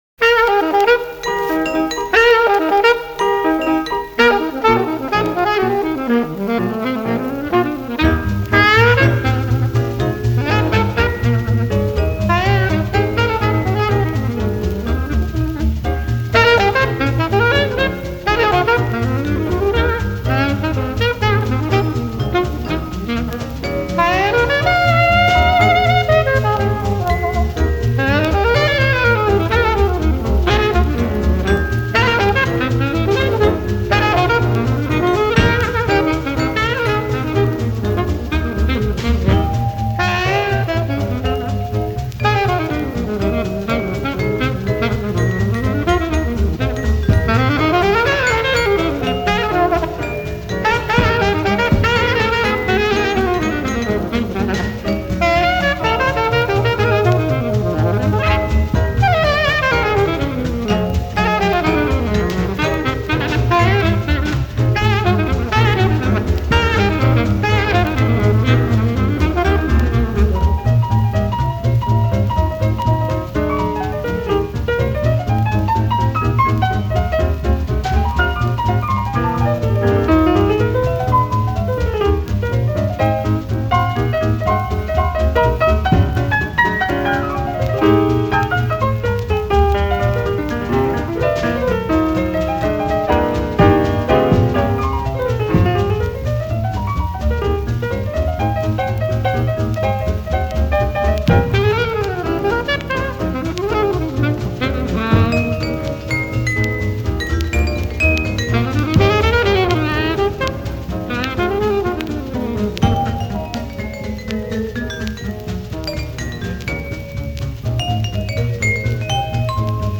Jazz in Switzerland (Vol. 1)